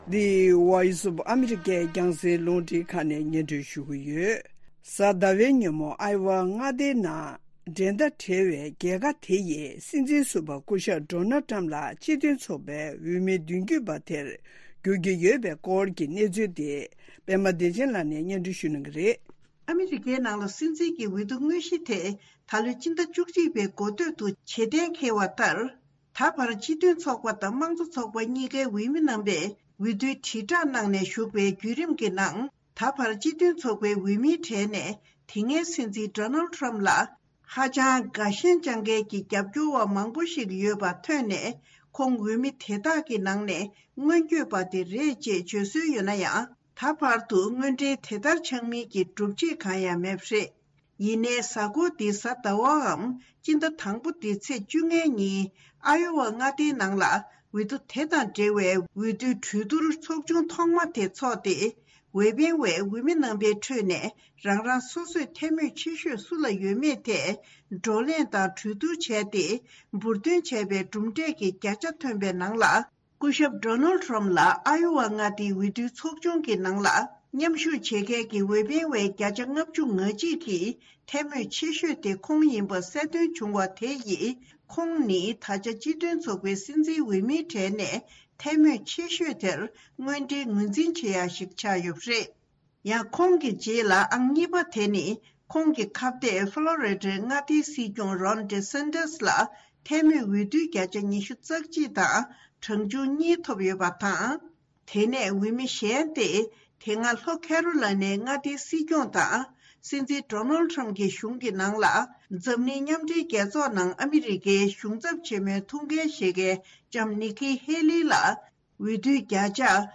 སྙན་སྒྲོན་རྗེས་གླེང་མོལ།